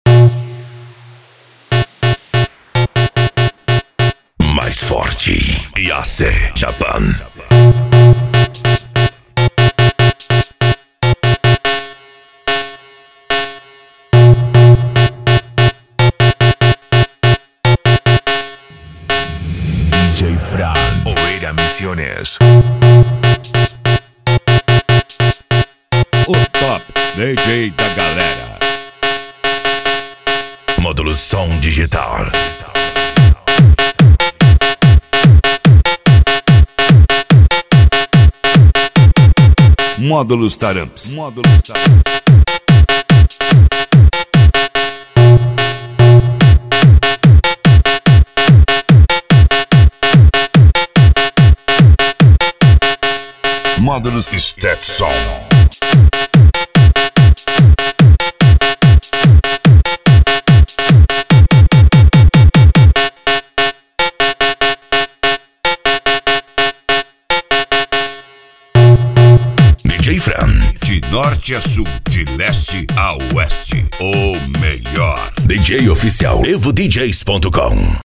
japan music